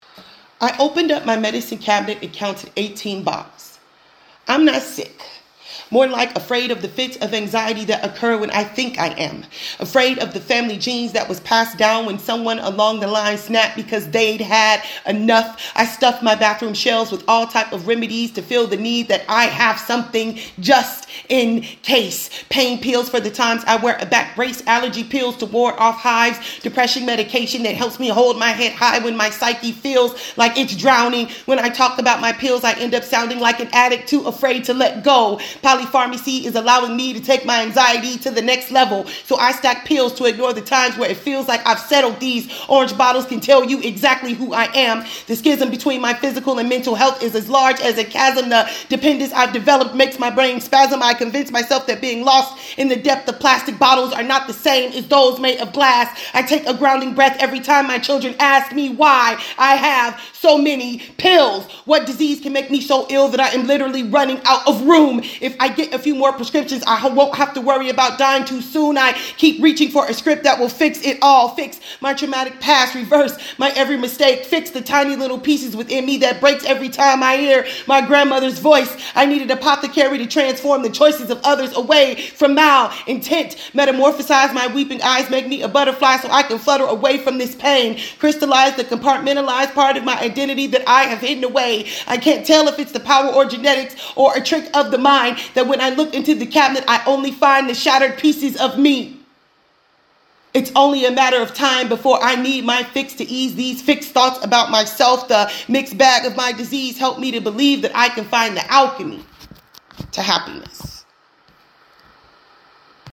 Member Poetry Readings